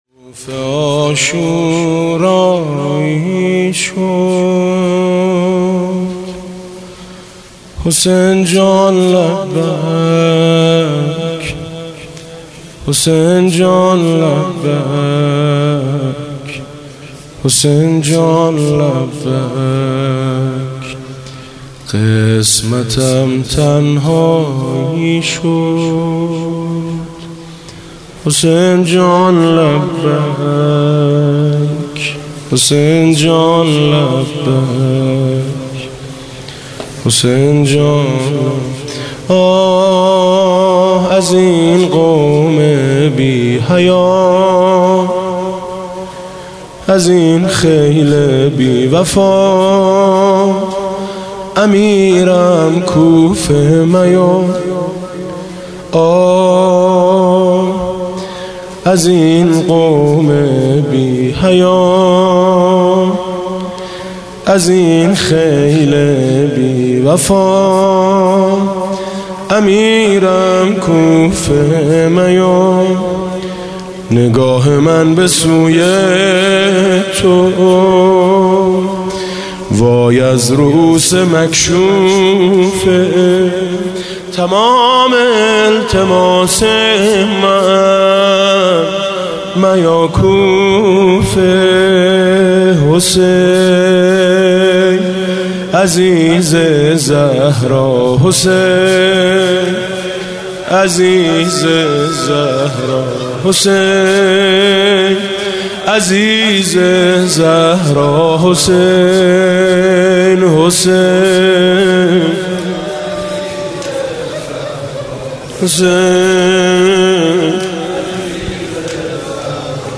زمرمه